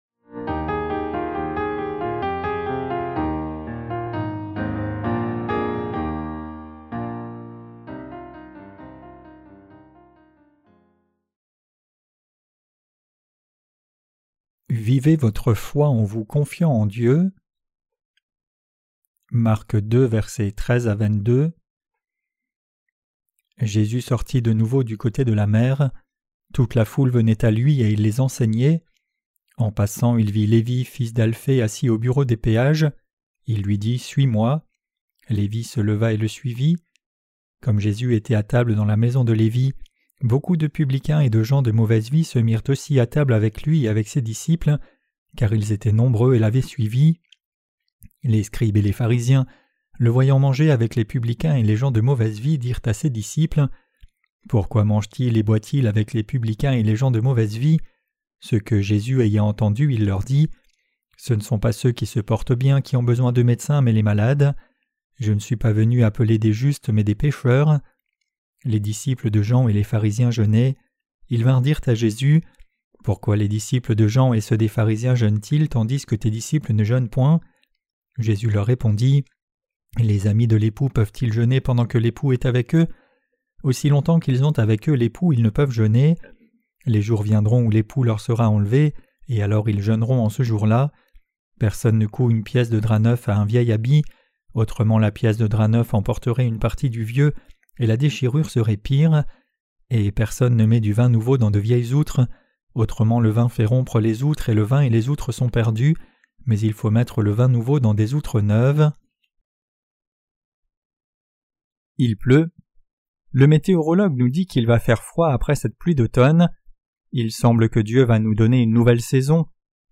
Sermons sur l’Evangile de Marc (Ⅰ) - QUE DEVRIONS-NOUS NOUS EFFORCER DE CROIRE ET PRÊCHER? 6.